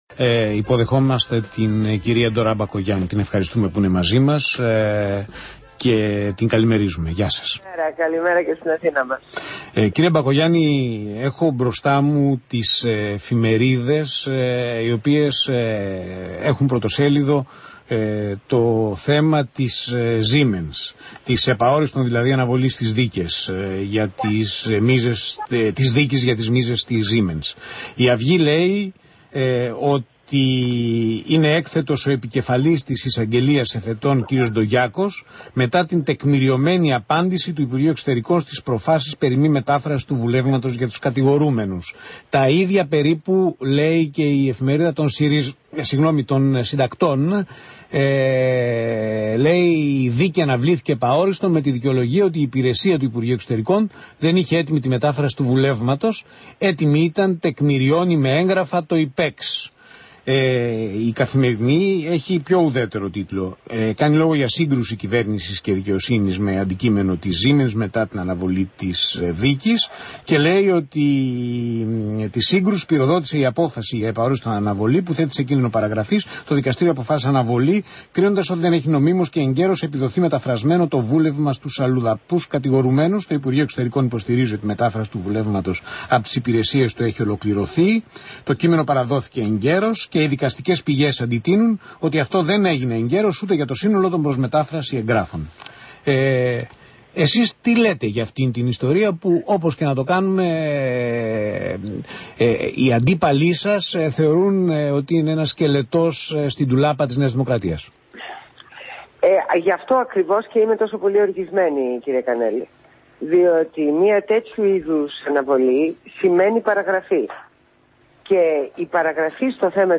Συνέντευξη στο ραδιόφωνο Αθήνα 9,84.